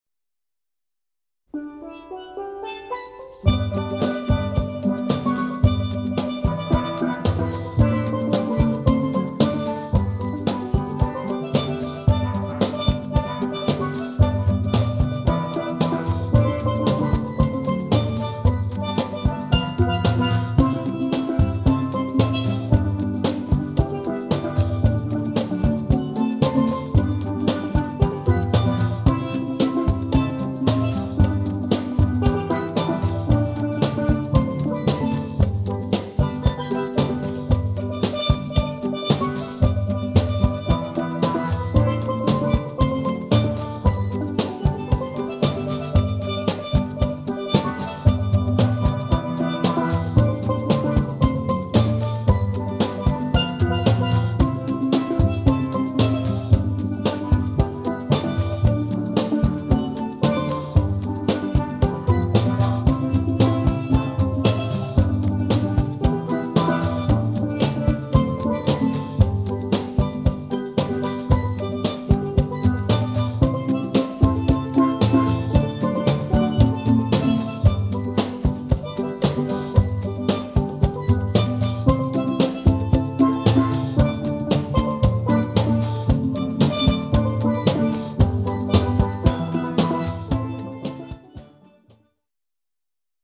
• Authentic Caribbean musicians and music
• Perfect to evoke sunny carnival ambience
• Versatile traditional steelpan ensemble